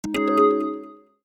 reminder.mp3